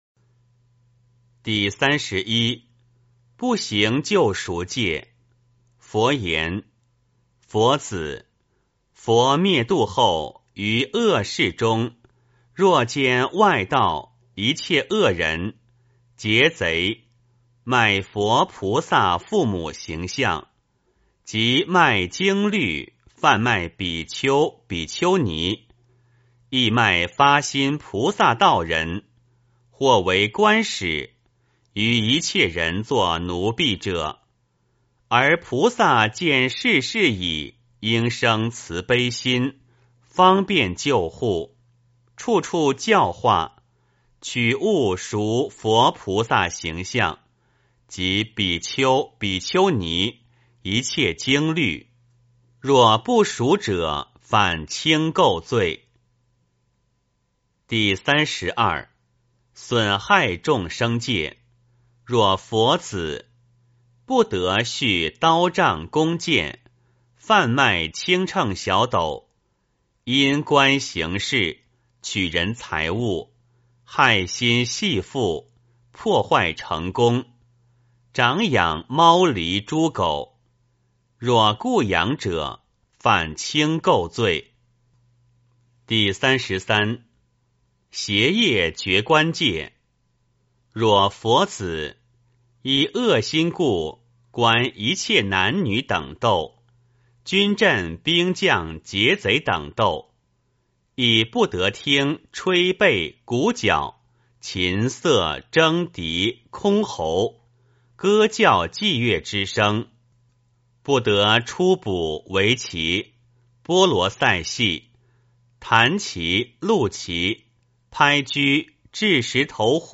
梵网经-四十八轻戒31 诵经 梵网经-四十八轻戒31--未知 点我： 标签: 佛音 诵经 佛教音乐 返回列表 上一篇： 梵网经-四十八轻戒 下一篇： 永嘉证道歌 相关文章 勿忘心安--张杰 勿忘心安--张杰...